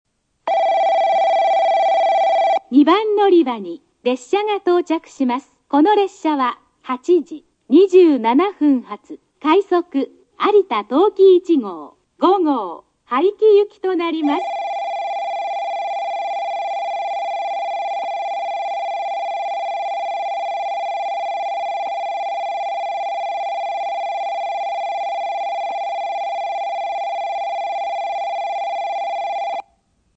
スピーカー：ソノコラム
接近放送（有田陶器市号・５号）　(147KB/30秒) 九州主要 ＭＤ
※2003年版の有田陶器市（有陶）の接近放送を収録できましたので載せておきます。